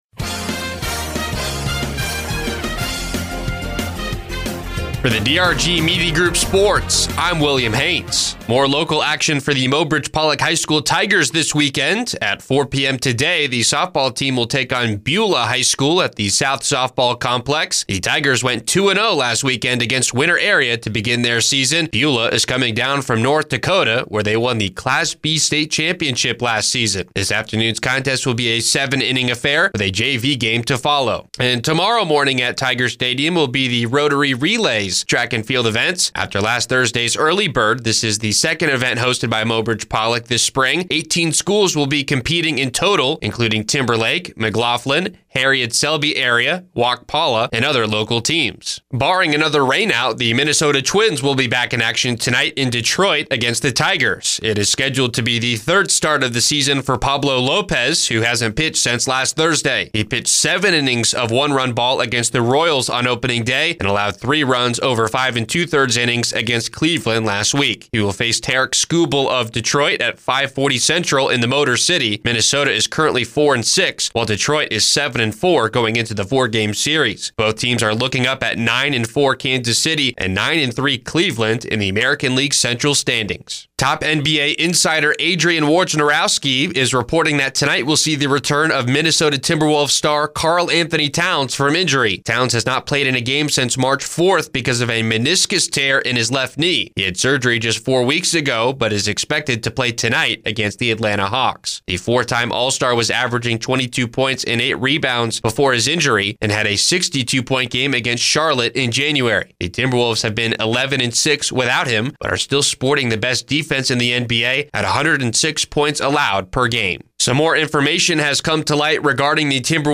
4-12-24-midday-sports.mp3